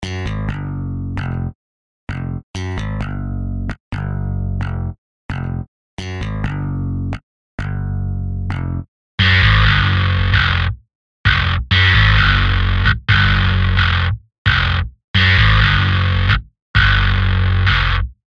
�������� ���� (�����������, Axe-FX Standard, Metal Foundry, Texas Grind Bass)